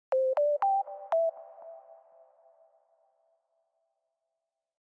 Beep Sound Button - Free Download & Play